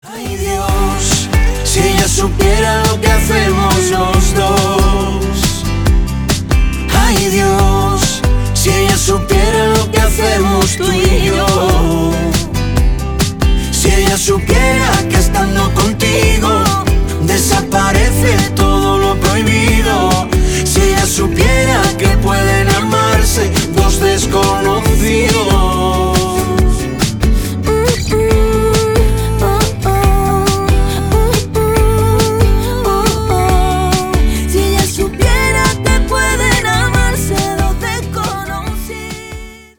Поп Музыка # латинские # спокойные